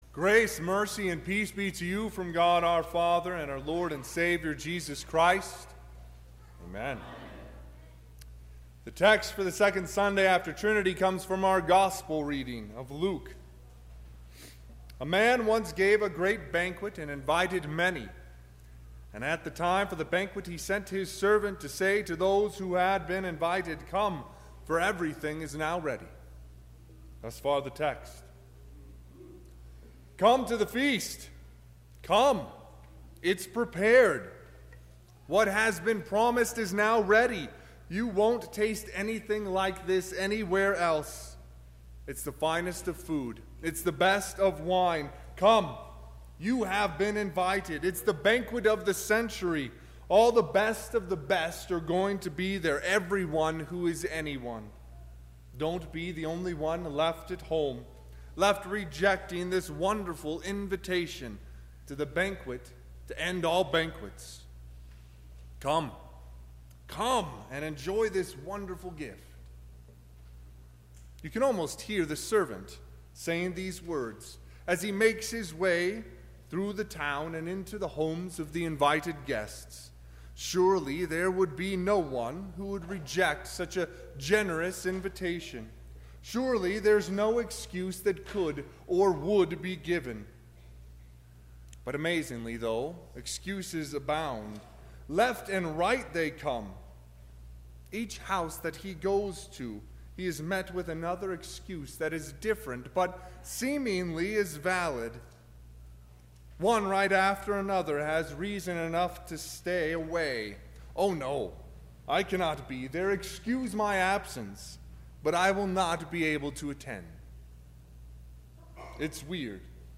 Second Sunday after Trinity
Sermon – 6/30/2019